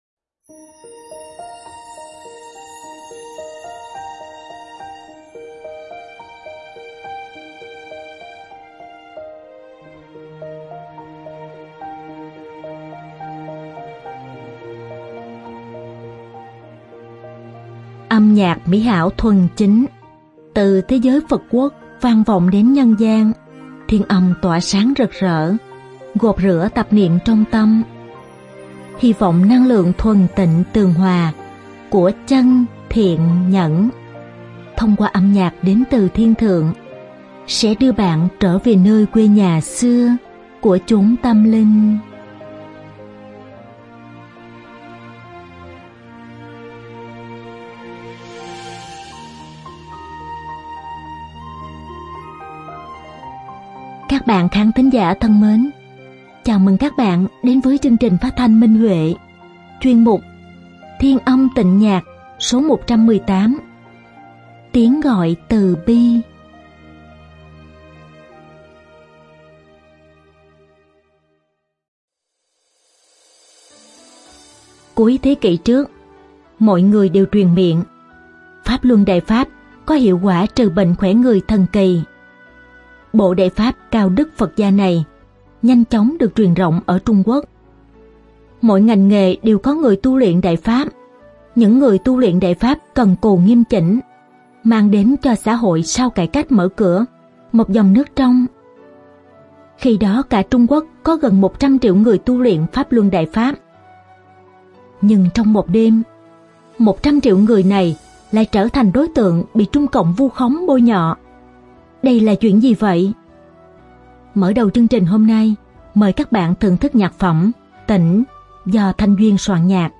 Đơn ca nữ
Đơn ca nam